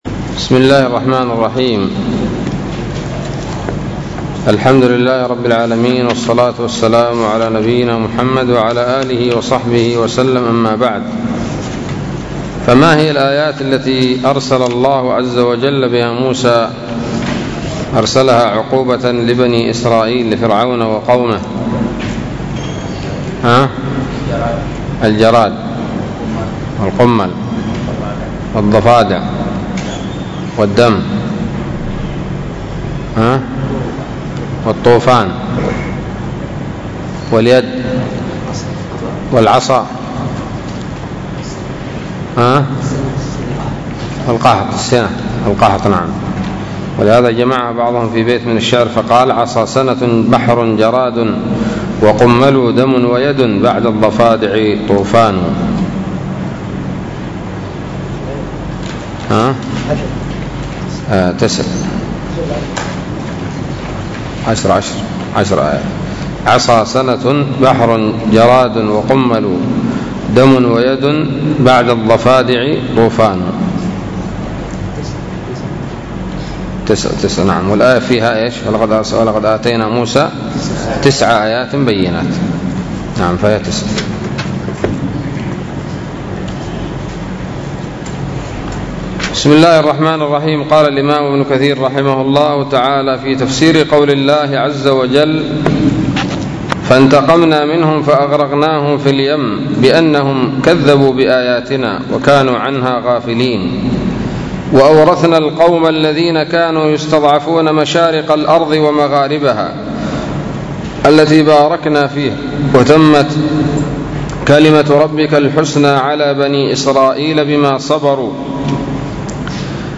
الدرس الثالث والأربعون من سورة الأعراف من تفسير ابن كثير رحمه الله تعالى